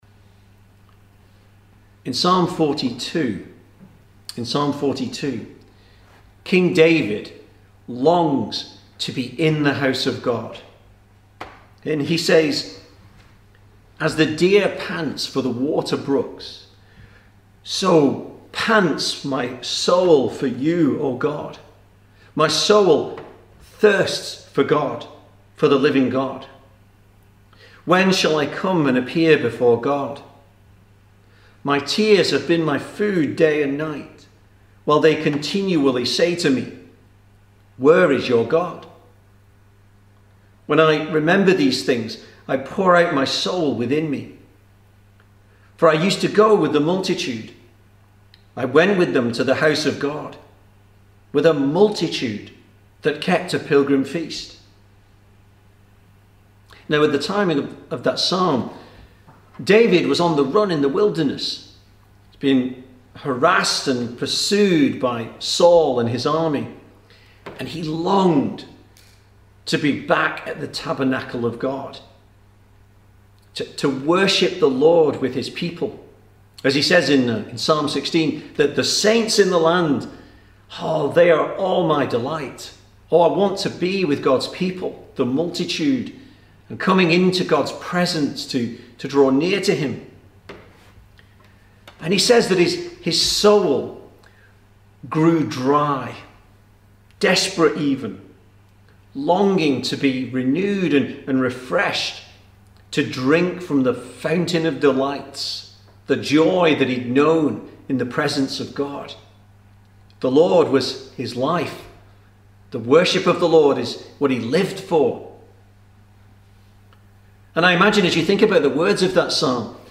2020 Service Type: Sunday Evening Speaker